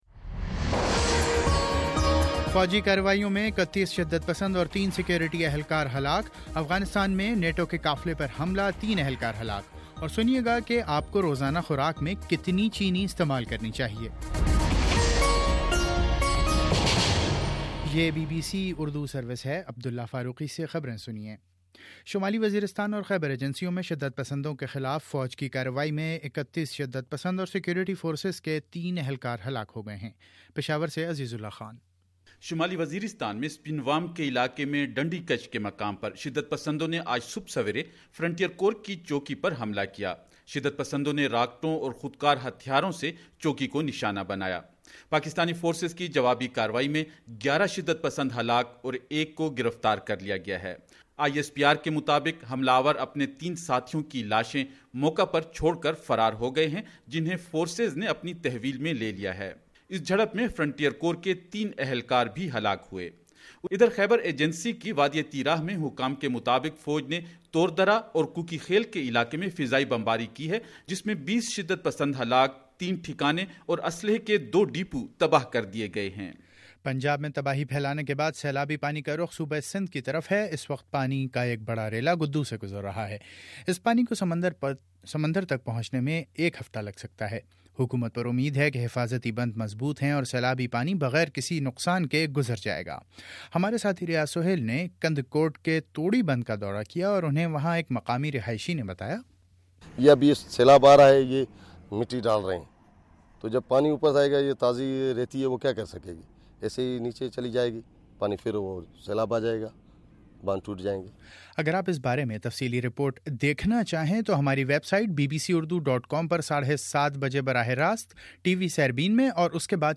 دس منٹ کا نیوز بُلیٹن روزانہ پاکستانی وقت کے مطابق صبح 9 بجے، شام 6 بجے اور پھر 7 بجے۔